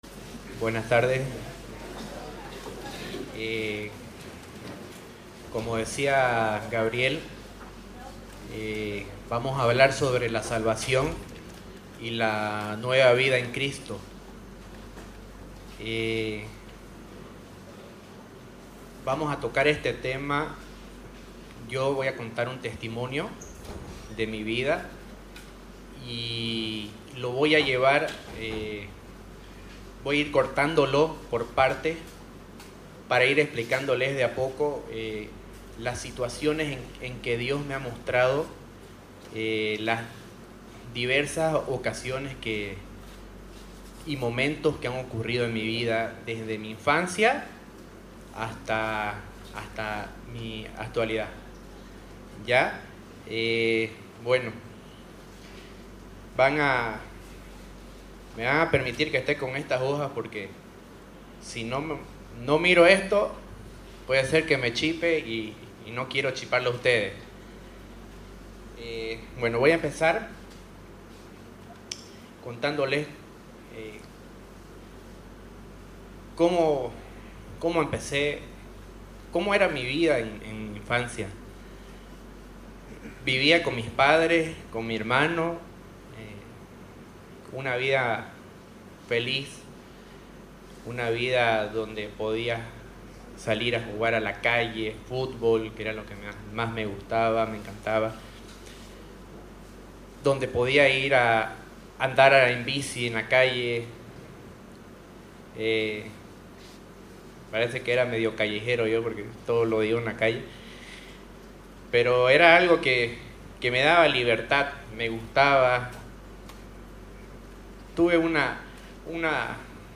Nueva Vida en Cristo - Testimonio